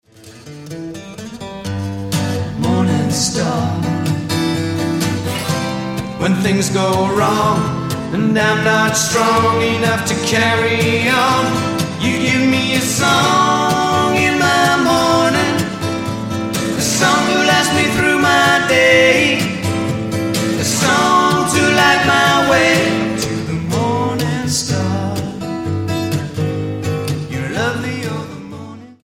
STYLE: Jesus Music
three bonus live tracks recorded in California